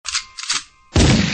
Descarga de Sonidos mp3 Gratis: beretta.
gun-beretta.mp3